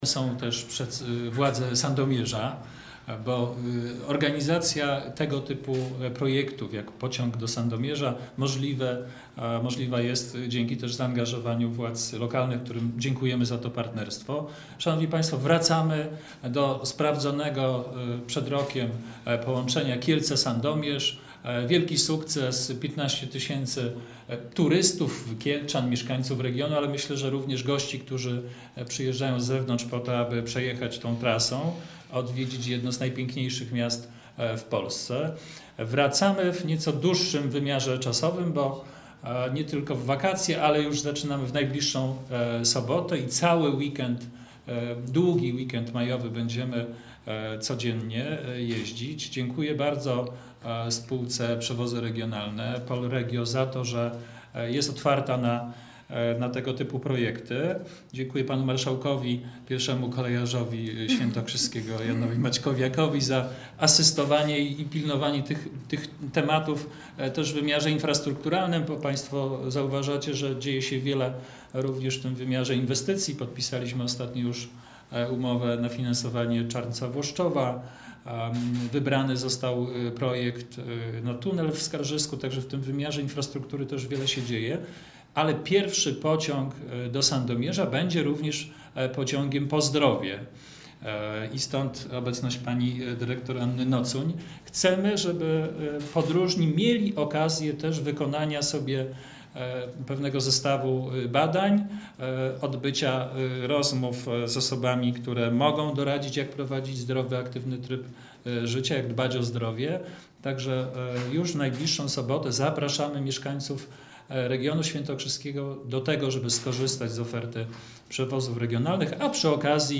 Briefing prasowy na temat pociągu do Sandomierza
marszałek-Adam-Jarubas.mp3